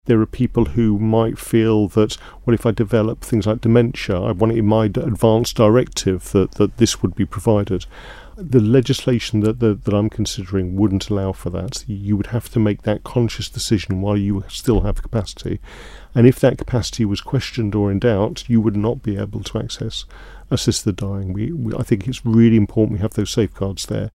It's after the House of Keys granted Ramsey MHK Alex Allinson permission to progress the proposals.
But he says it has to be a decision made by the individual: